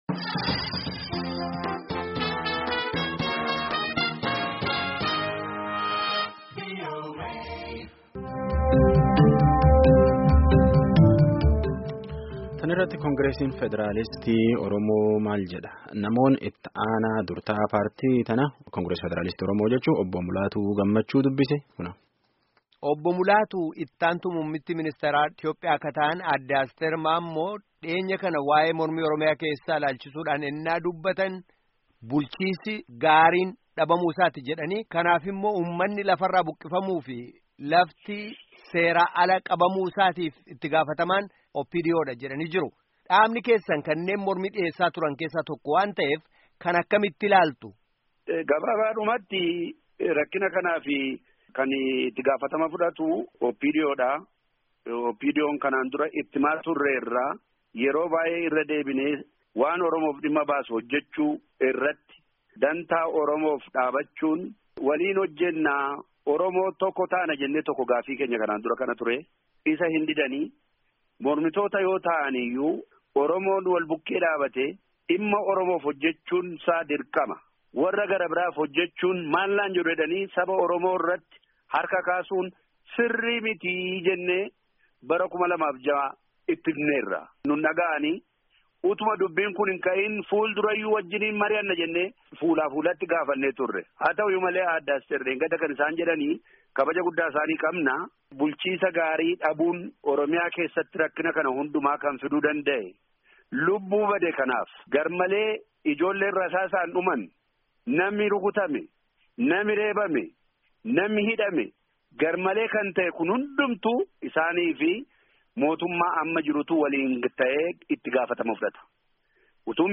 Guutummaa gaaffii fi deebii itti-aanaa dura-taa’aa Koongiresa Federaalawa Oromoo waliin geggeessamee dhaggeeffadhaa